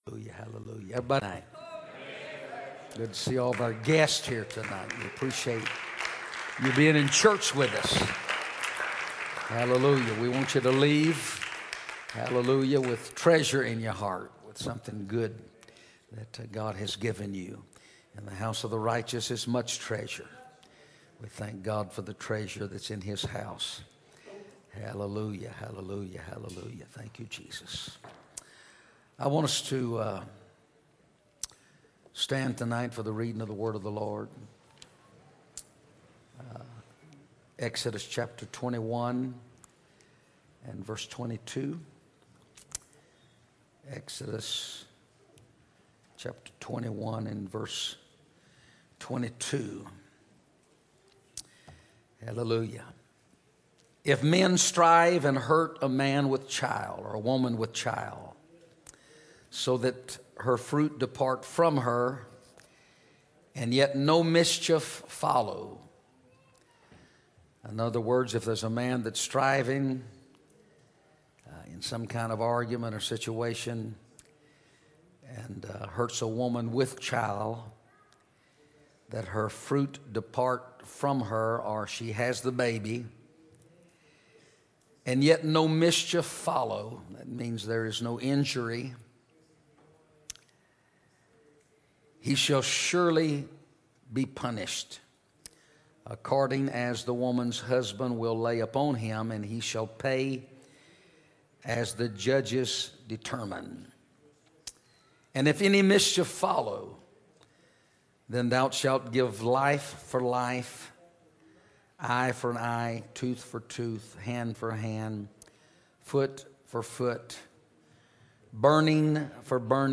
First Pentecostal Church Preaching 2017